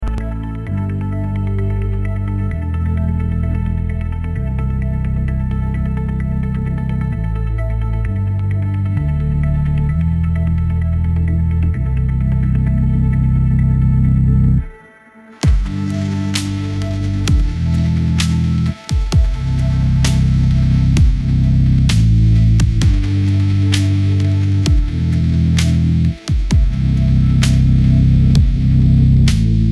🎶 Quelques réalisations musicales générées par nos participants avec MusiGen
Futuristic music 🛸 Science and Physics 🧬Sun and joy, violin 🌞
futuristic_music_with_scienceandphysics.mp3